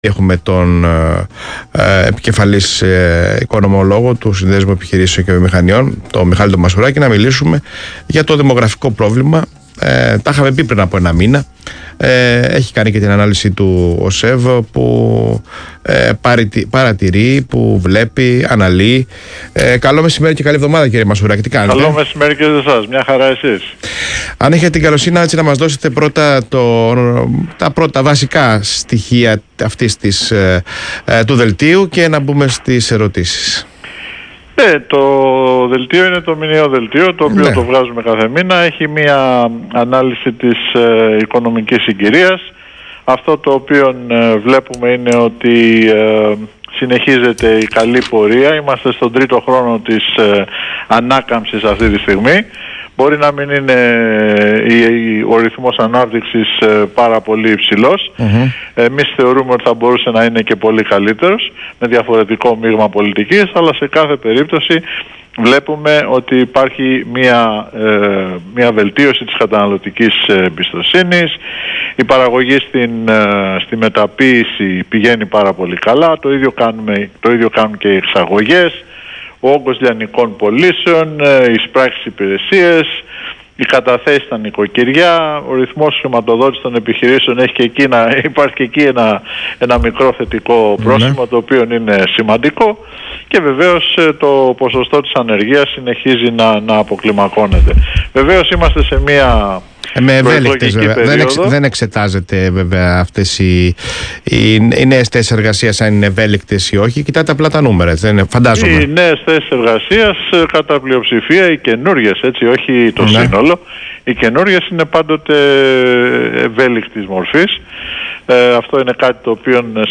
στον Ρ/Σ ALPHA RADIO